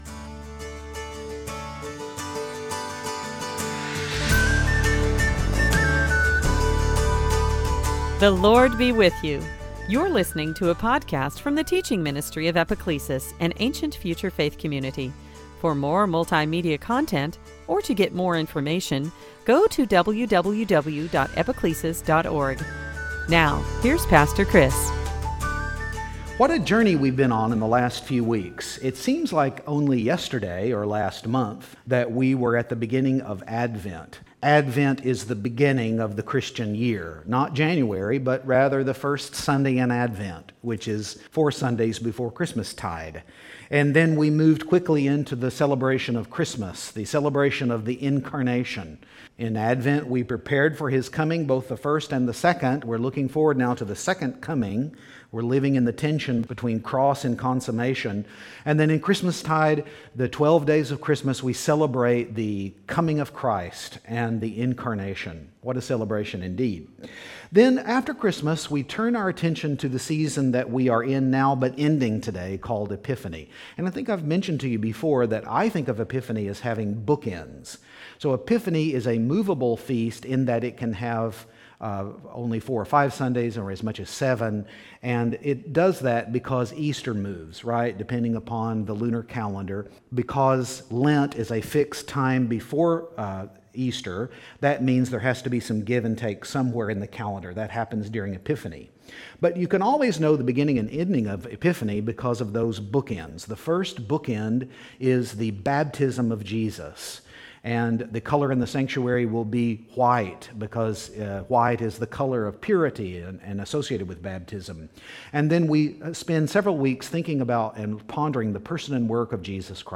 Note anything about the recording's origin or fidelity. Service Type: Transfiguration Sunday